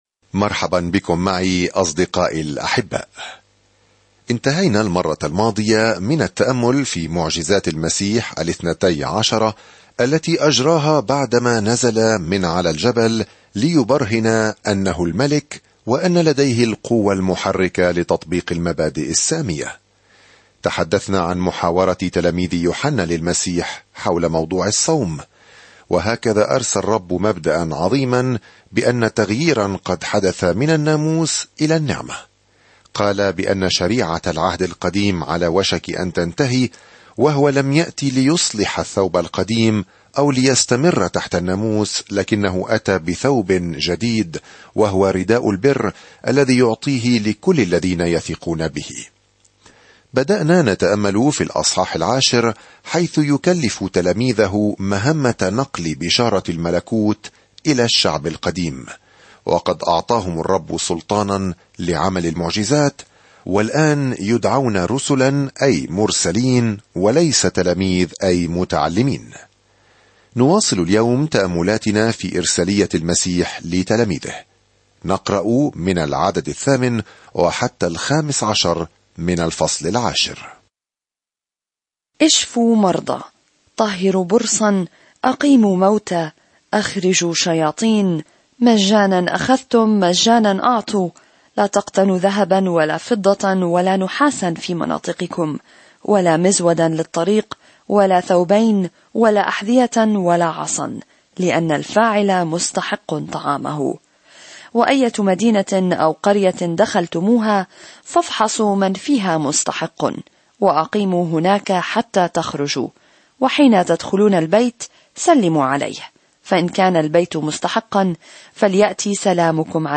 الكلمة مَتَّى 8:10-42 مَتَّى 1:11-6 يوم 15 ابدأ هذه الخطة يوم 17 عن هذه الخطة يثبت متى للقراء اليهود الأخبار السارة بأن يسوع هو مسيحهم من خلال إظهار كيف حققت حياته وخدمته نبوءة العهد القديم. سافر يوميًا عبر متى وأنت تستمع إلى الدراسة الصوتية وتقرأ آيات مختارة من كلمة الله.